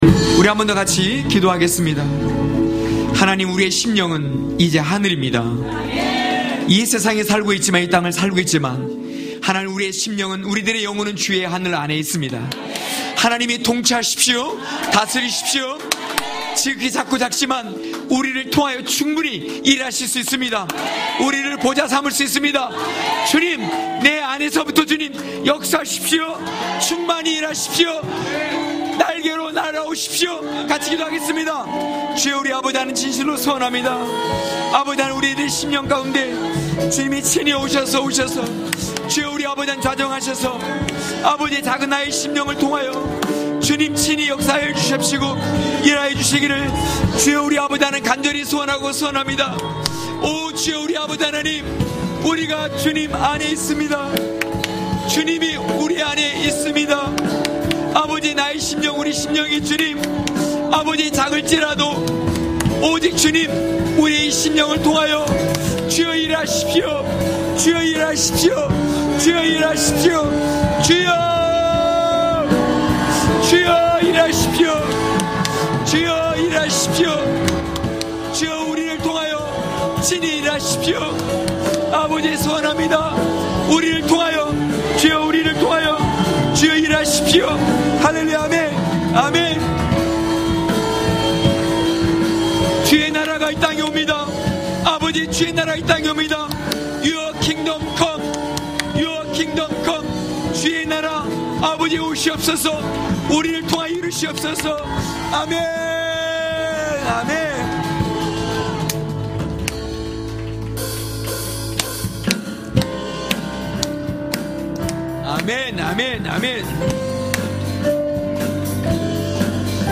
강해설교 - 21.다윗, 느헤미야의 때...(느12장46절~13장9절).mp3